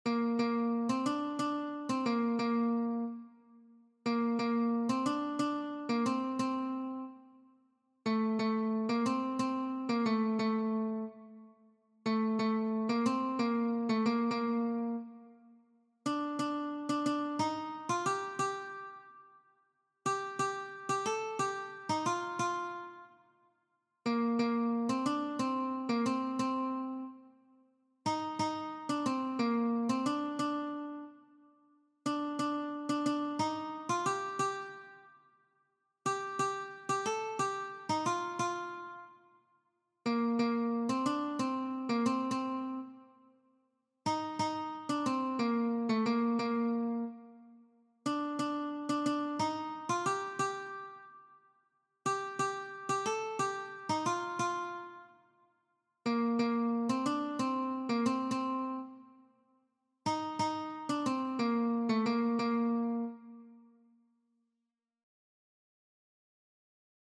Bertso melodies - View details   To know more about this section
Hamabiko berdina, 6 puntuz eta 8 silabaz (hg) / Sei puntuko berdina, 16 silabaz (ip)
8/ 8A/ 8/ 8A/ 8/ 8A/ 8/ 8A/ 8/ 8A/ 8/ 8A (hg) / 16A/ 16A/ 16A/ 16A/ 16A/ 16A (ip)